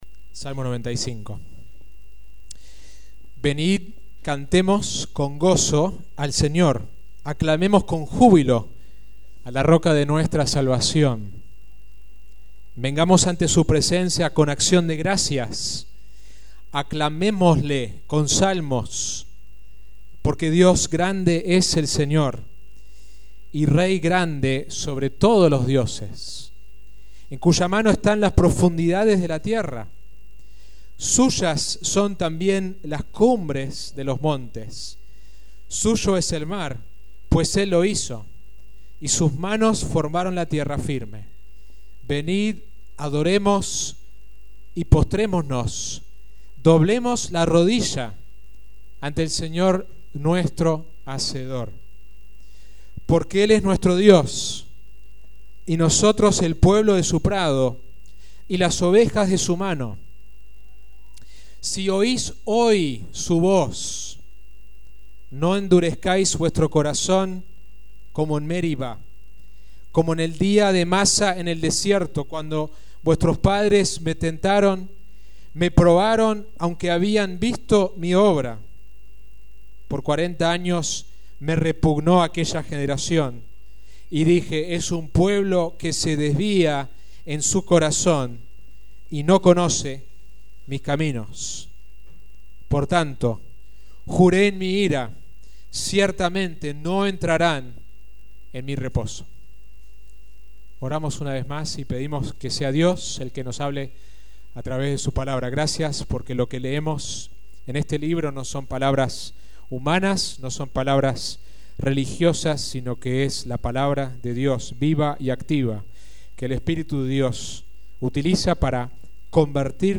Sermón